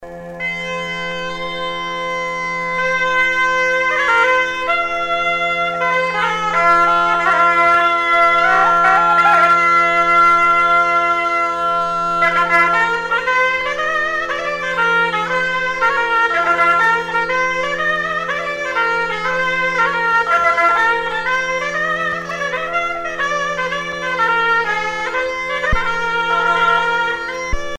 danse : fisel (bretagne)